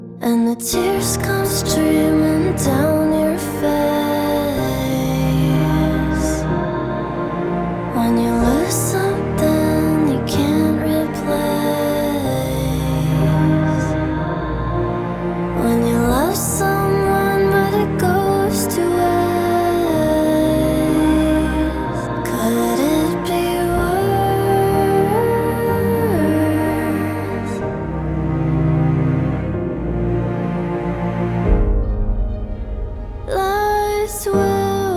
Alternative
Жанр: Альтернатива